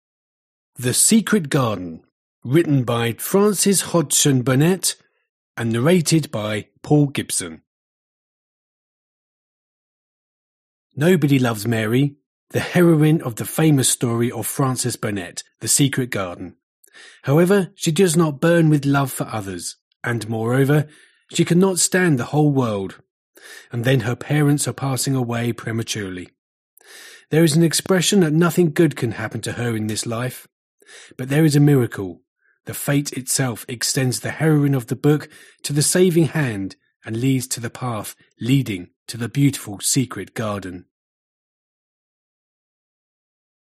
Аудиокнига The Secret Garden | Библиотека аудиокниг